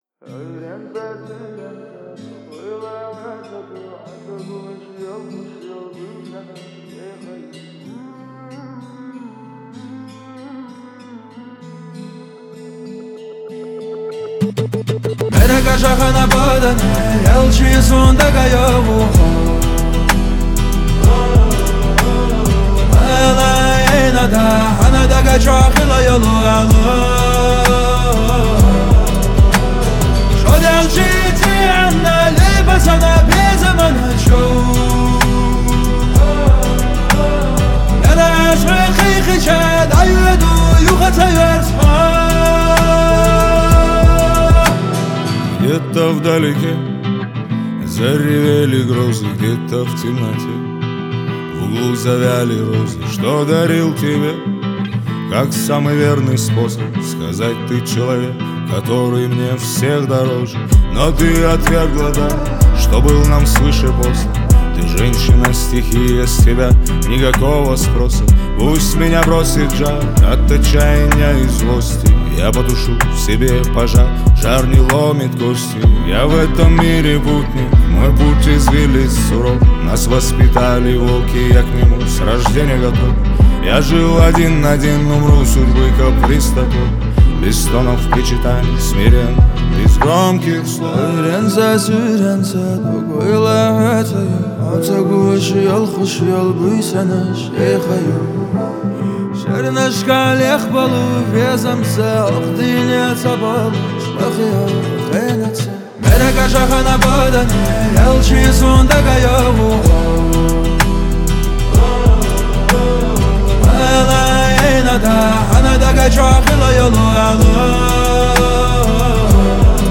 Жанр: Чеченские